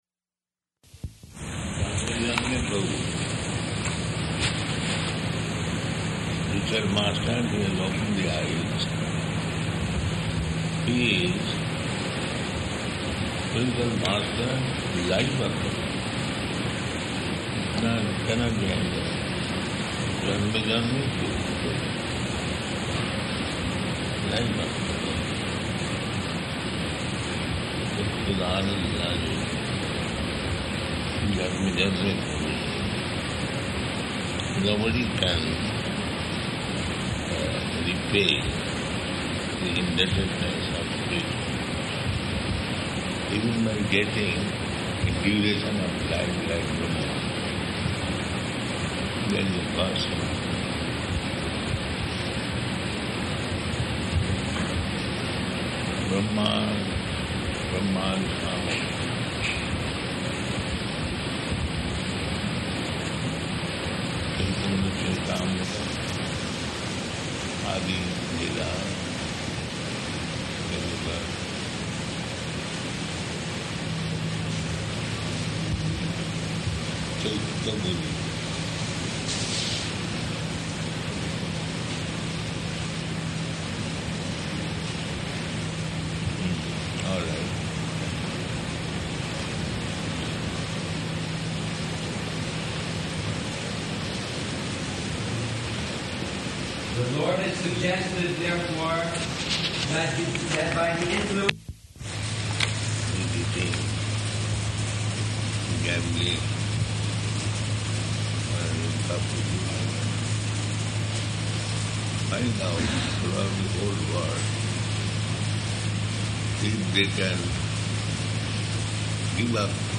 Conversations with Kīrtana Groups and Sannyāsa Initiation --:-- --:-- Type: Conversation Dated: May 29th 1977 Location: Vṛndāvana Audio file: 770529R2.VRN.mp3 Prabhupāda: Janme janme prabhu sei.